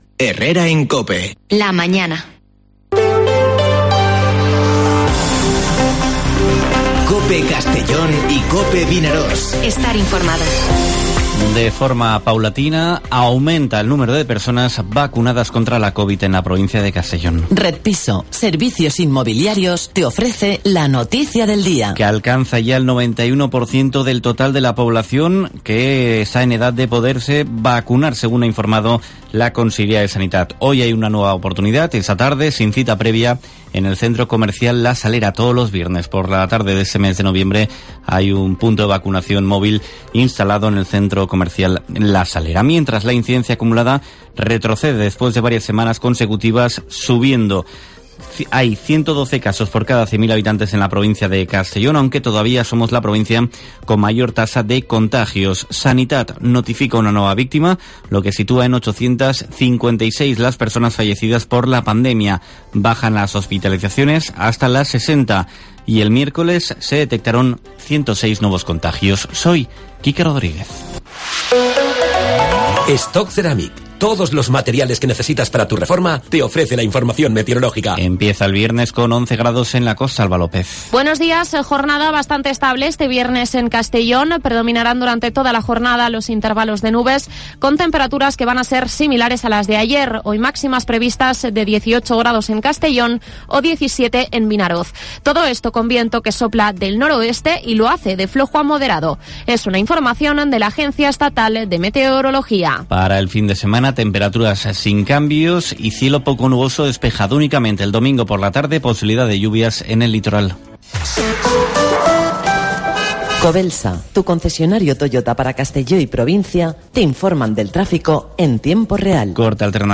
Informativo Herrera en COPE en la provincia de Castellón (05/11/2021)